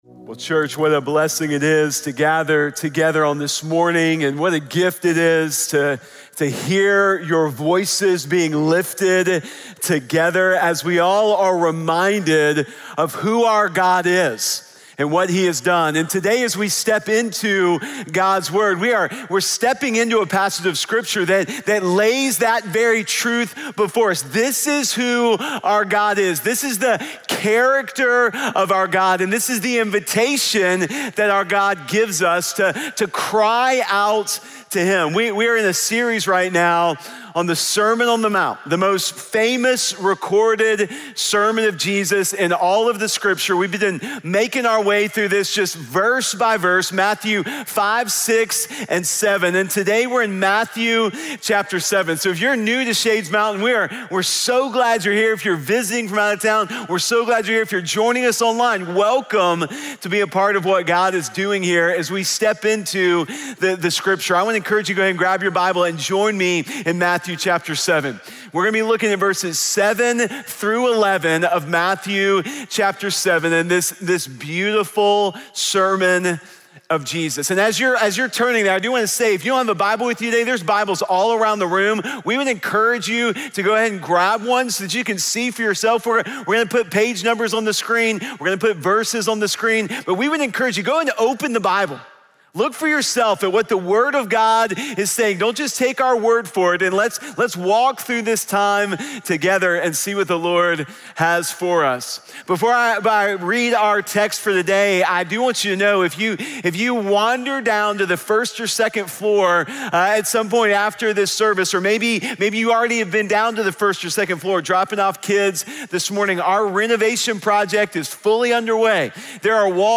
Solid Foundation | Bold Prayers Sermon podcast